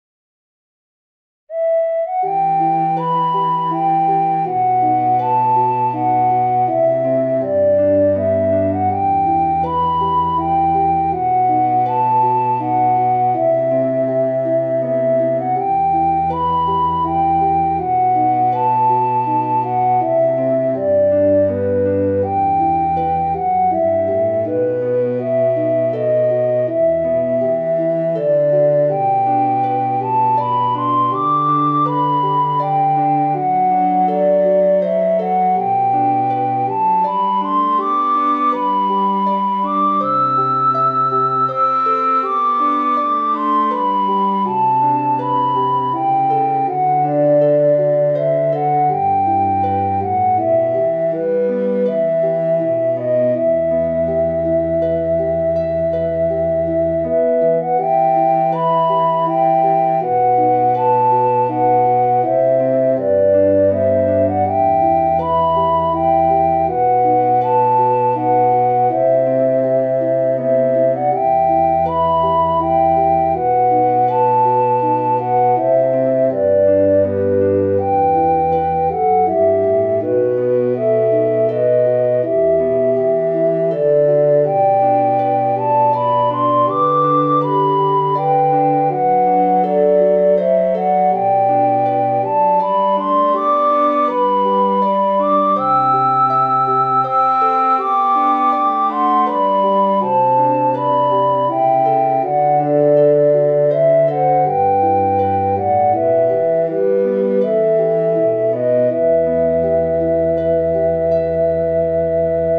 pretty.mid.ogg